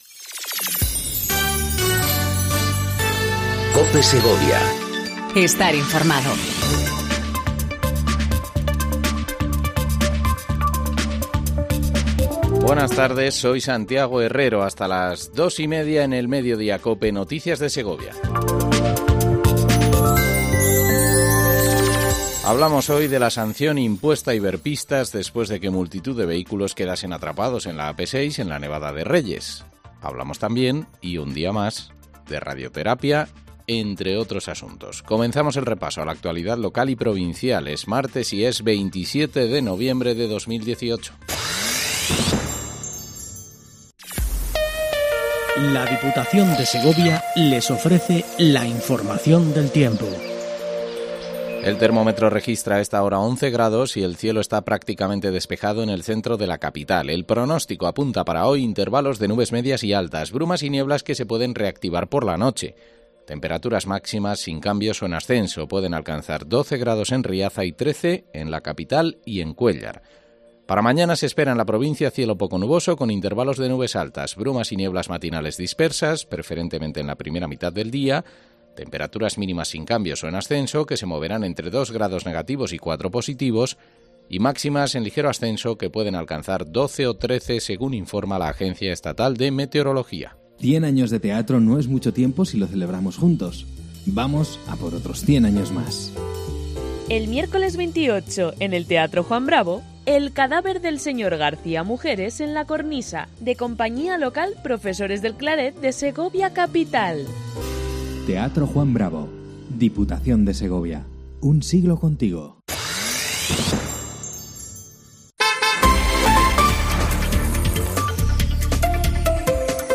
AUDIO: Repaso informativo a la actualidad local y provincial 27/11/18